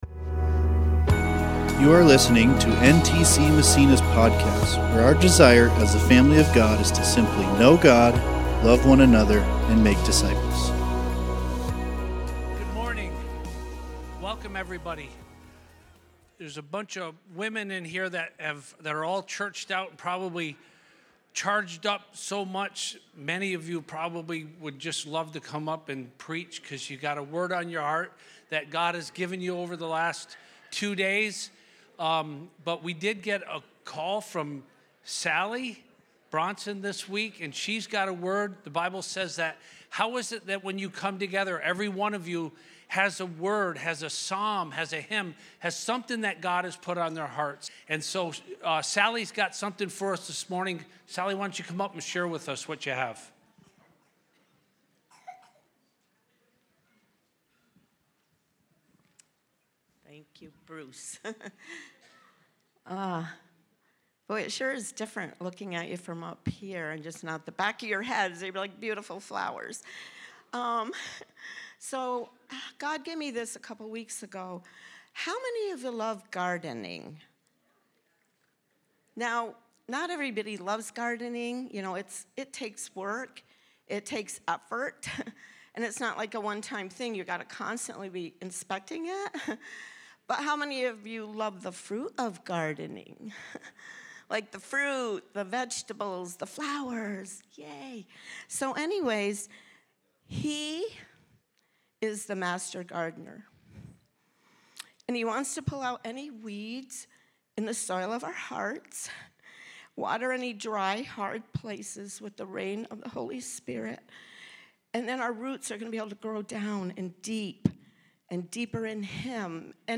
Peter Preacher